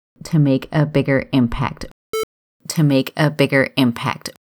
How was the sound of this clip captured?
Audio critique? It sounds like I'm talking in a box. Adjusting the bass & treble in Audacity can make it a lot less muddy … Even the best mic money can buy will not get rid of the boxiness & reverb which are both from sound reflections in the room. … The muffled sound is still making me twitch…